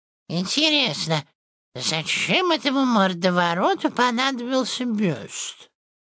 Образцы озвучания, прошедшие визирование у Супера и допущенные к опубликованию: